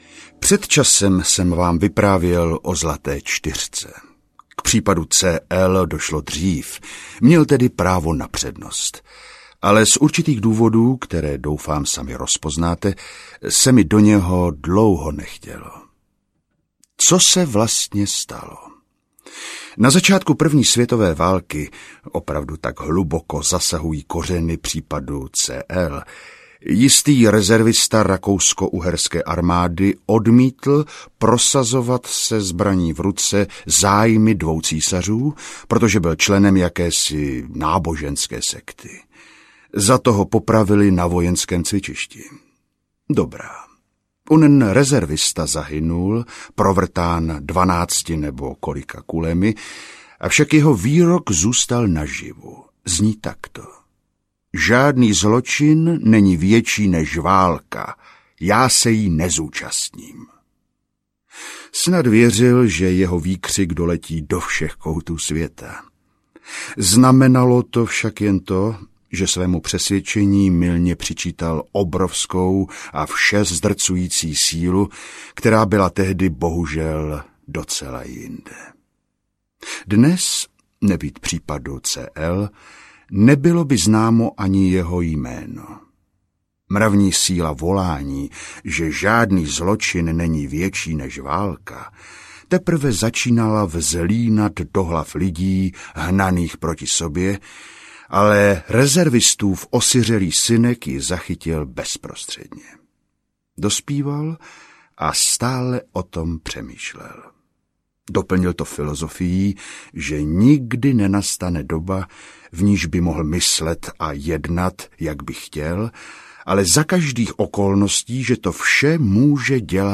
Série C-L audiokniha
Ukázka z knihy